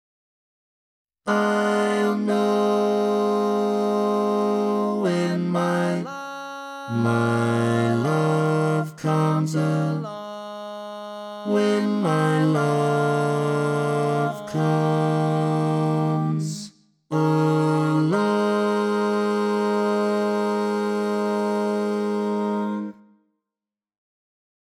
Key written in: A♭ Major
Type: Barbershop